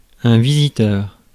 Ääntäminen
Ääntäminen France: IPA: [vi.zi.tœʁ] Haettu sana löytyi näillä lähdekielillä: ranska Käännös Ääninäyte Substantiivit 1. visitor US 2. caller Suku: m .